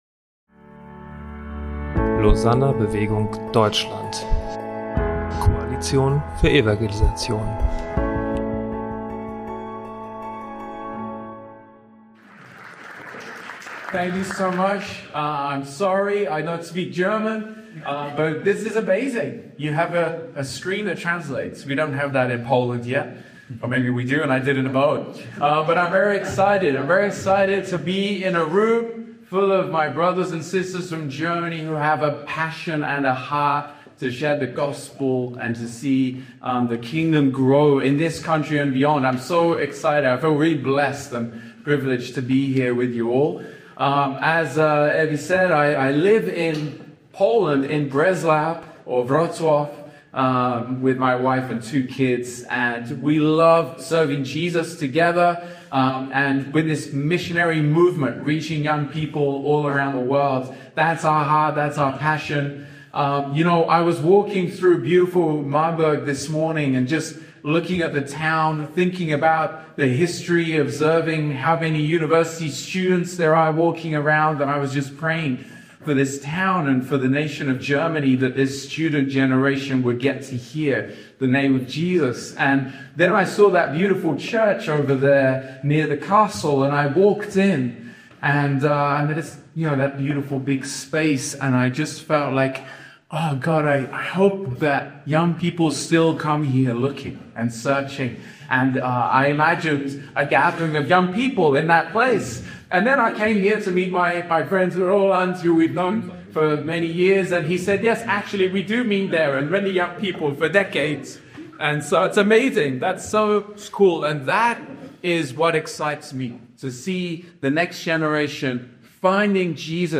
Keynote